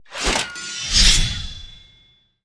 tank_skill_shieldbuff_start.wav